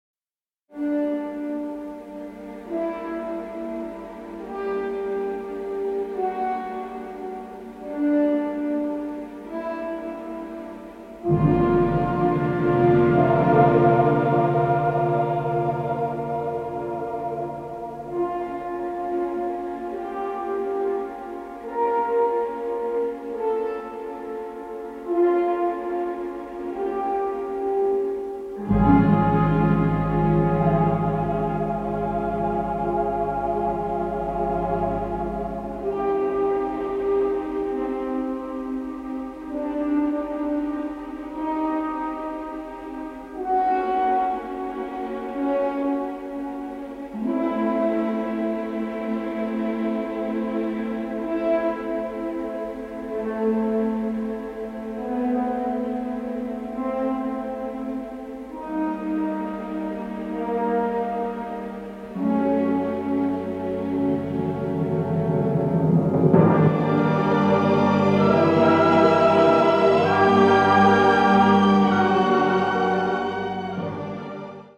THE FILM SCORE (MONO)